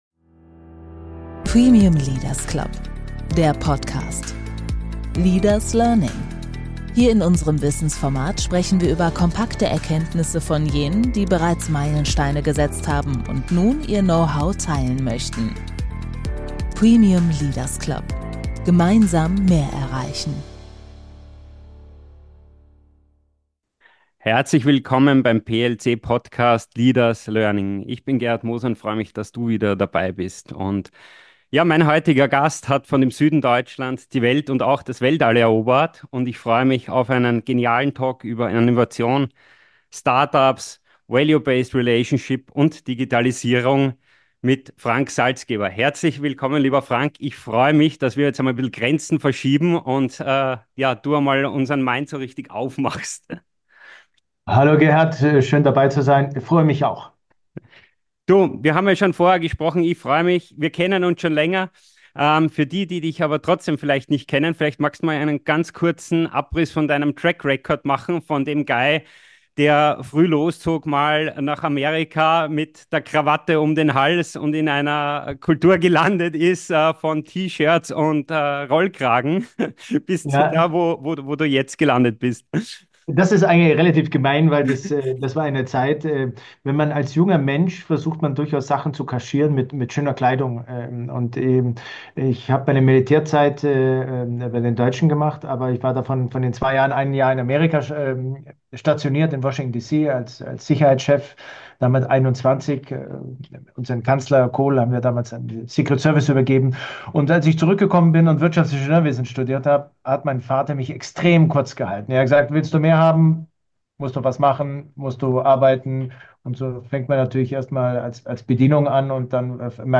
Während in Europa oft Probleme im Fokus stehen, erlebt er dort eine Mentalität des Machens und Gestaltens. Ein inspirierendes Gespräch über Mut, Veränderung und die Kraft neuer Perspektiven.